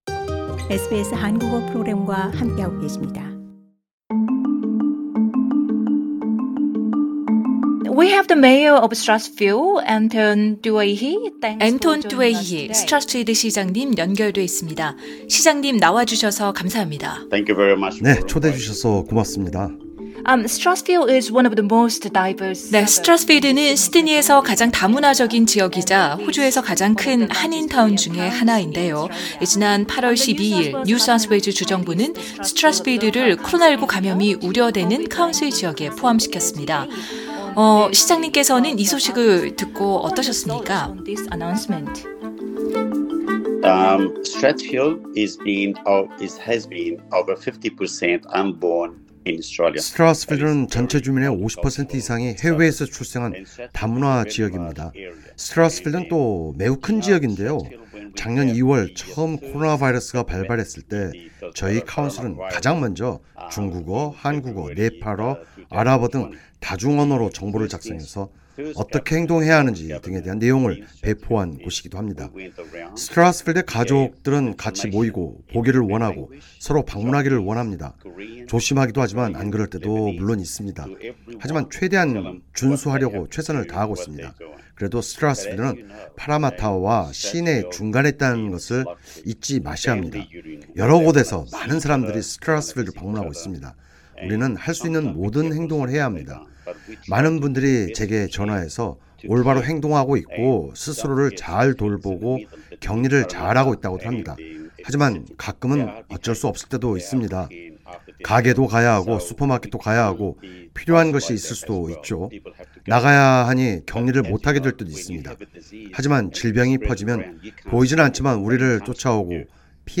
Mayor of Starthfield, Cr Antoine Doueihi Source: Mayor of Starthfield, Cr Antoine Doueihi